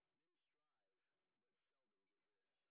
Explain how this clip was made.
sp06_exhibition_snr20.wav